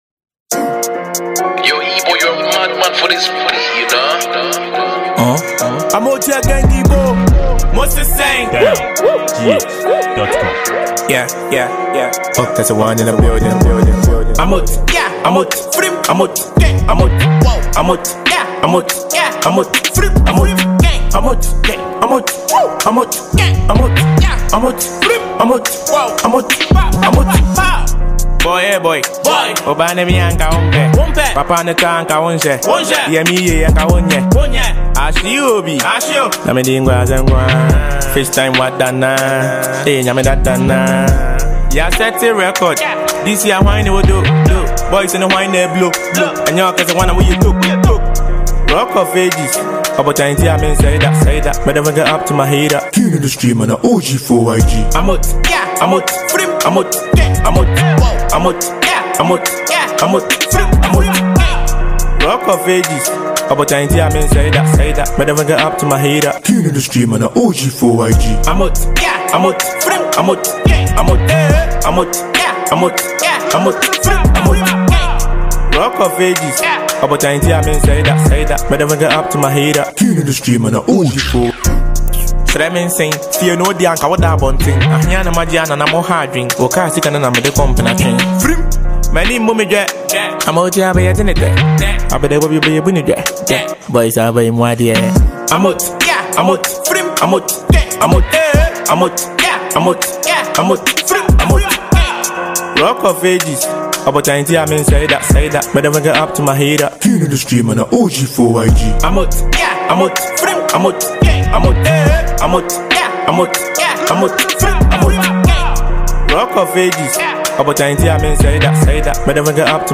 Ghana Music
a Ghanaian rapper and songwriter